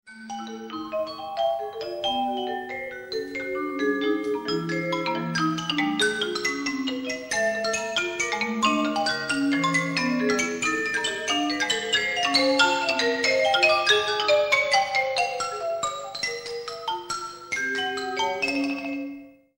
Ö1 (rec. 18.05.1995 Krems)